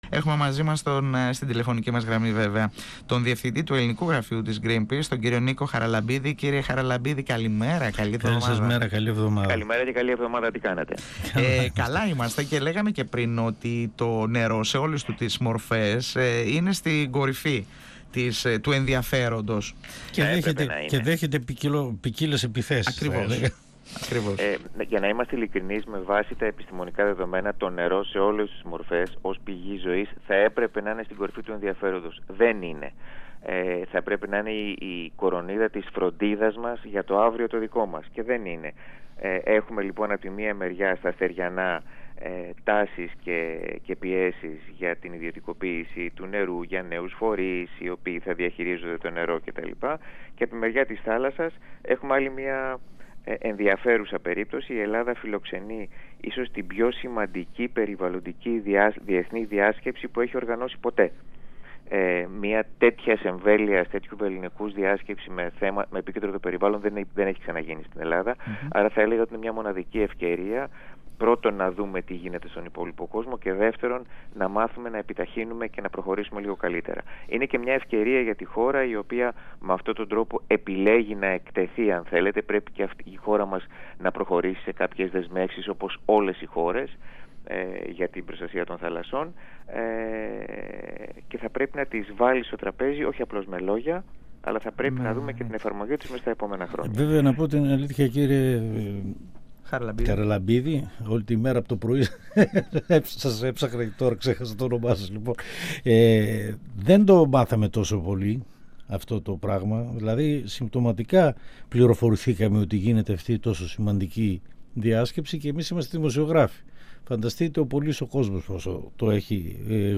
στον 102fm της ΕΡΤ3